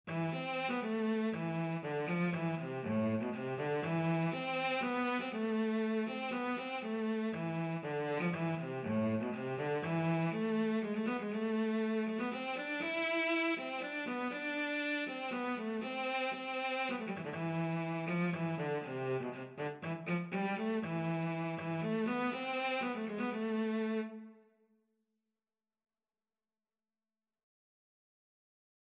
Free Sheet music for Cello
3/4 (View more 3/4 Music)
A minor (Sounding Pitch) (View more A minor Music for Cello )
Cello  (View more Easy Cello Music)
Traditional (View more Traditional Cello Music)